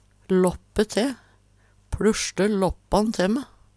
låppe te - Numedalsmål (en-US)